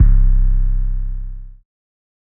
spinz808.wav